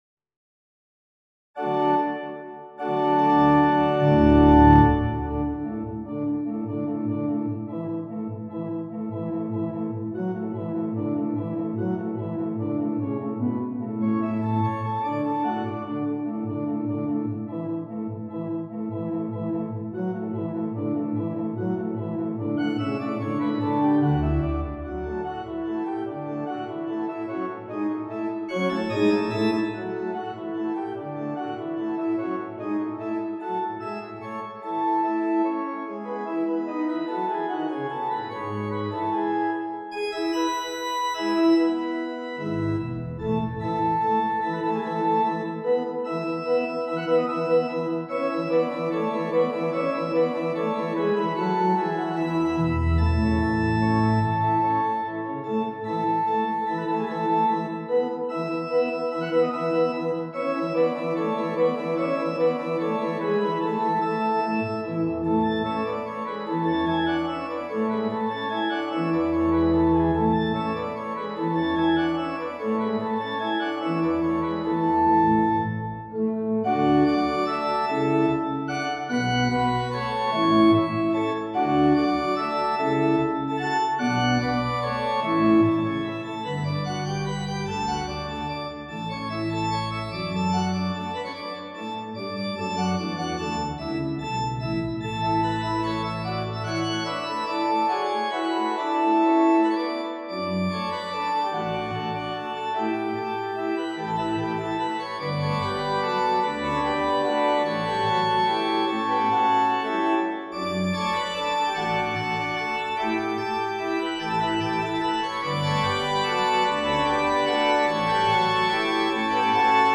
for organ
The sixteen-measure subject is spun out in tonic, subdominant and dominant tonal regions.